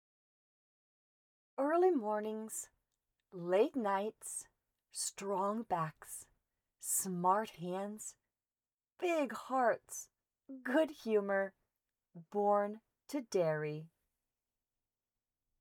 Professional-grade recording equipment and acoustically treated space